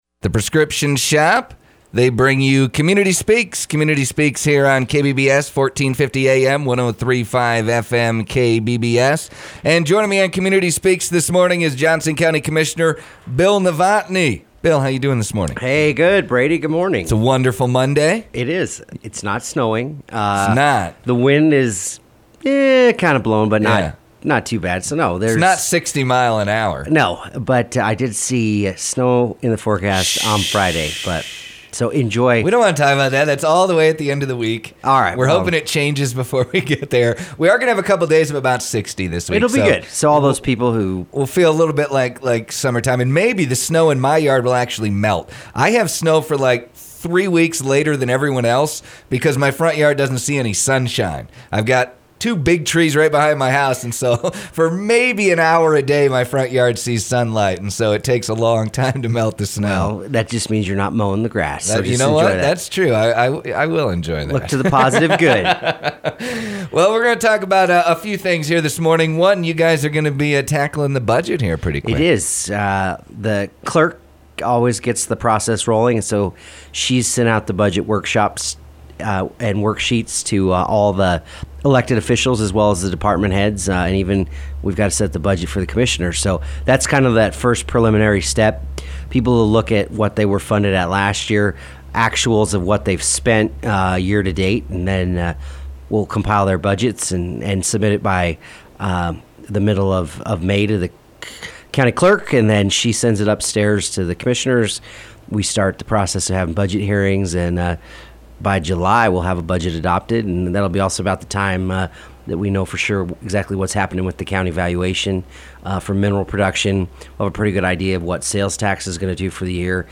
Johnson County Commissioner, Bill Novotny was a recent guest on KBBS to discuss their upcoming Budget meetings, Construction Project on TW Road in Buffalo, and some upcoming Public Meetings.